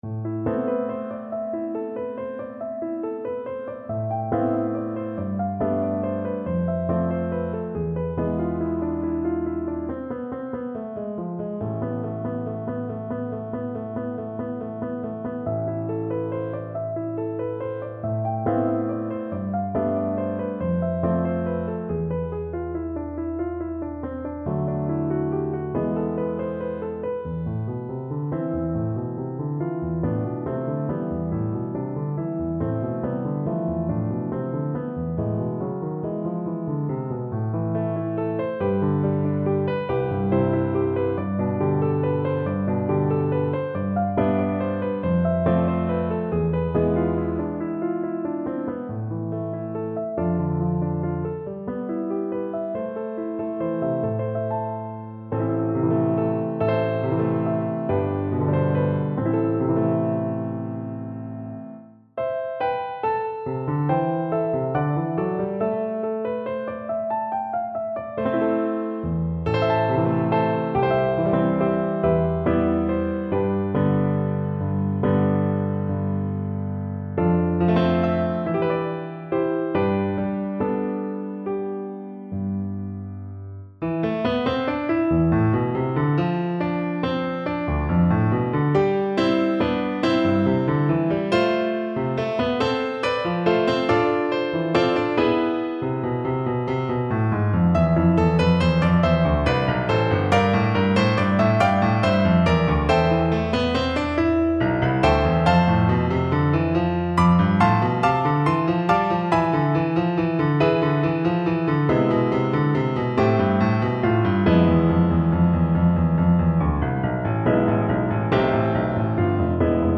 A haunting arrangement of this famous British folk melody.
Fast, flowing =c.140
3/4 (View more 3/4 Music)
Traditional (View more Traditional French Horn Music)